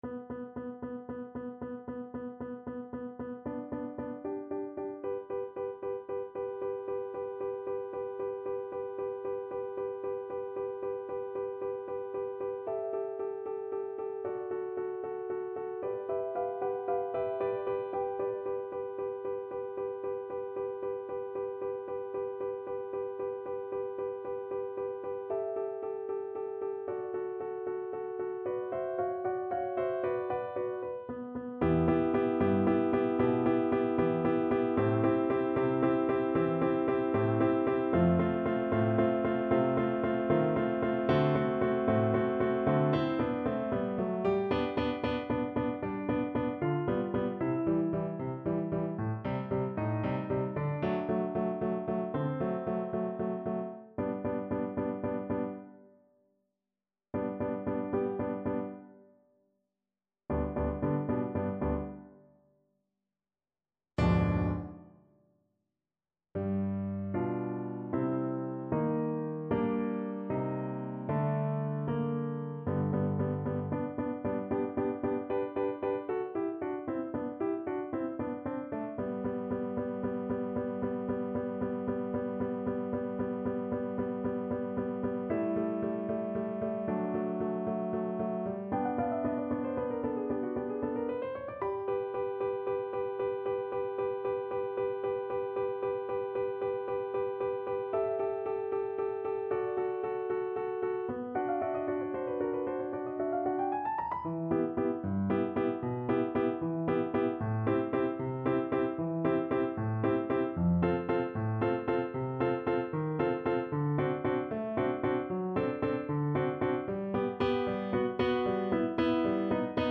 Moniuszko: Aria Skołuby (na skrzypce i fortepian)
Symulacja akompaniamentu